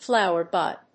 アクセントflówer bùd